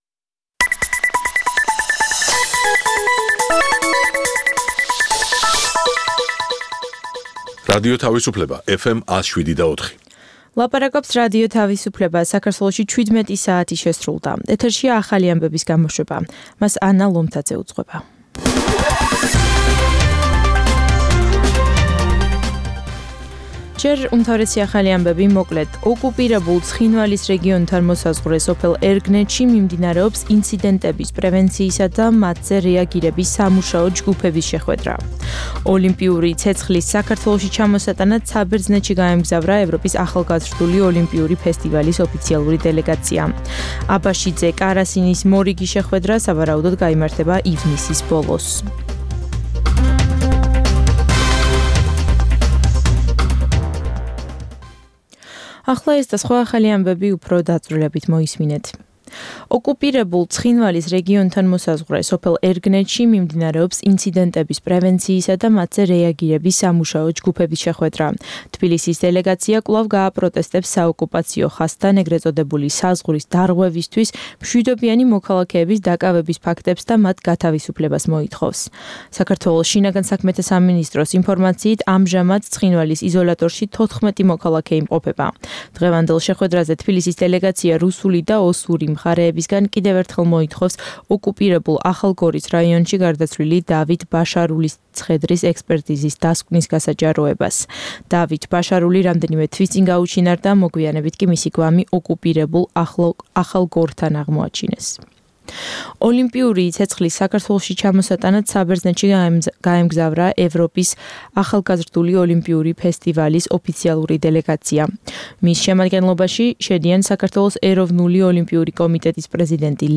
ცნობილ ექსპერტებთან ერთად, გადაცემაში მონაწილეობენ საზოგადოებისთვის ნაკლებად ცნობილი სახეები, ახალგაზრდა სამოქალაქო აქტივისტები. გამოყენებულია "რადიო თავისუფლების" საარქივო მასალები, რომელთაც გადაცემის სტუმრები "დღევანდელი გადასახედიდან" აფასებენ.